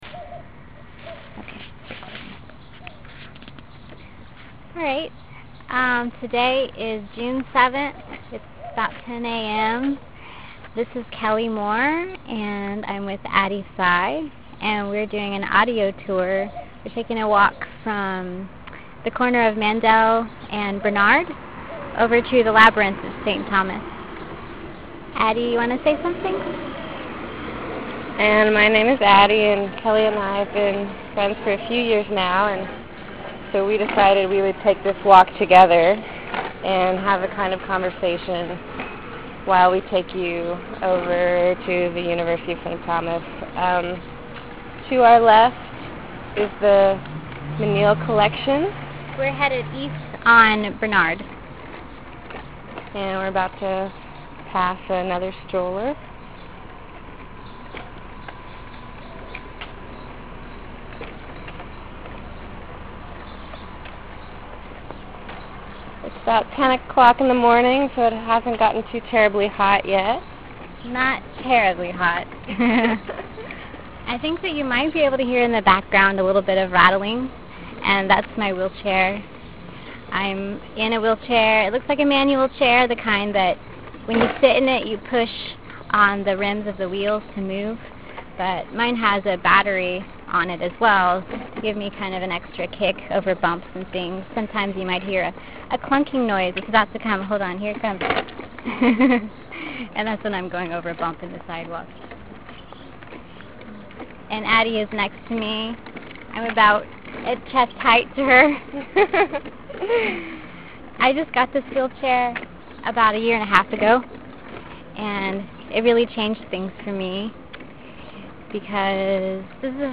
From Mandell at W Main St to St Thomas Labrynth